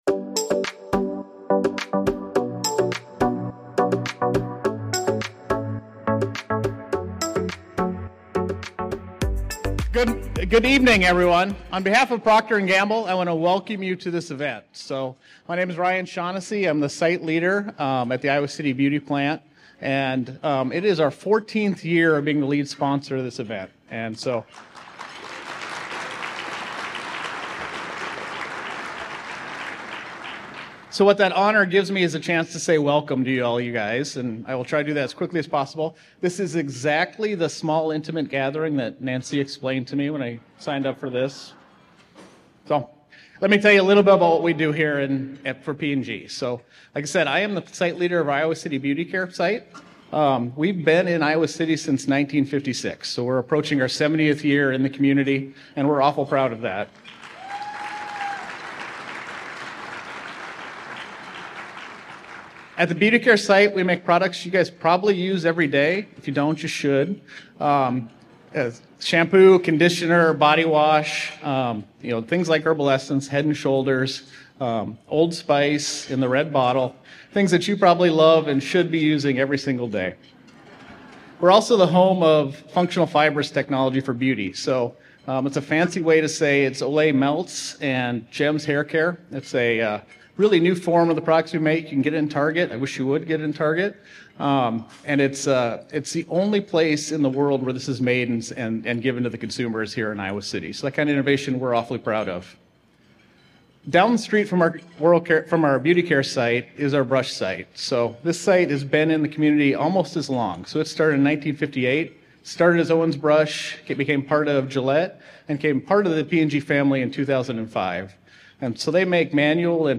The program includes a presentation of Greater Iowa City, Inc.'s Annual Report, offering a comprehensive look at the initiatives, events, and outcomes that shaped the past year.